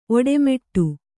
♪ oḍemeṭṭu